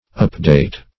update \up"date\ ([u^]p"d[=a]t`), n.
update \up"date\ ([u^]p"d[=a]t` or [u^]p*d[=a]t"), v. t.